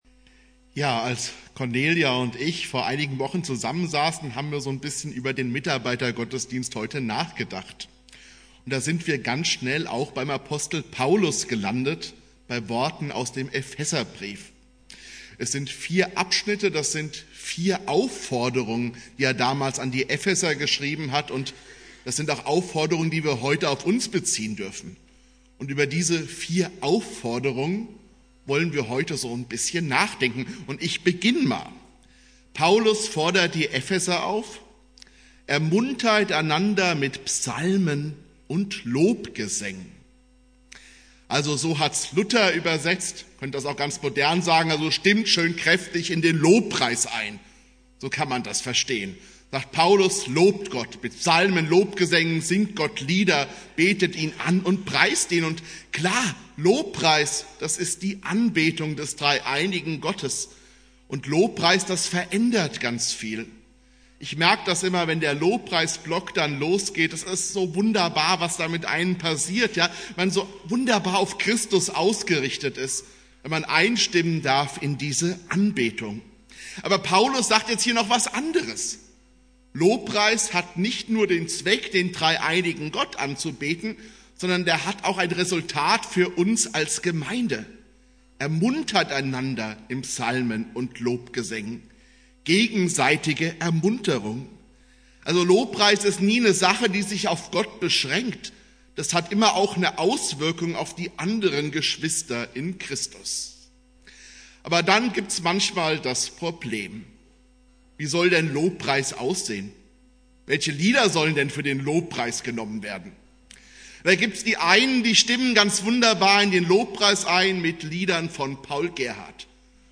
Dialogpredigt im Mitarbeiter-Dankgottesdienst